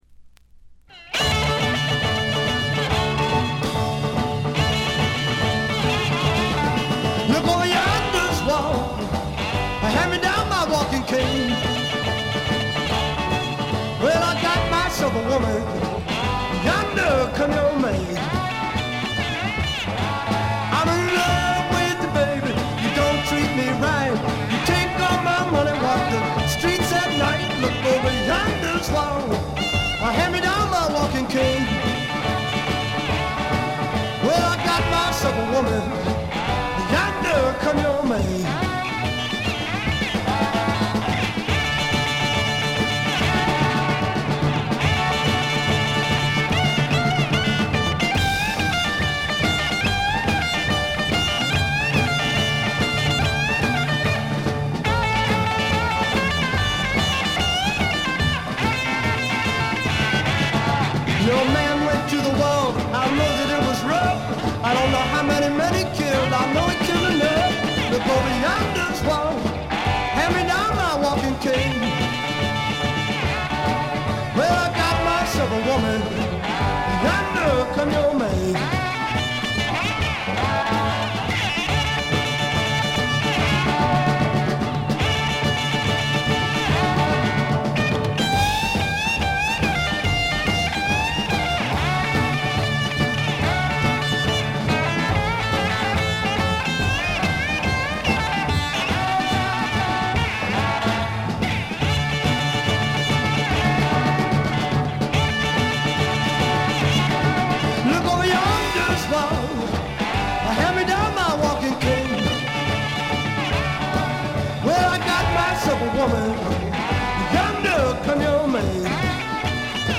暴風雨のような凄まじい演奏に圧倒されます。
試聴曲は現品からの取り込み音源です。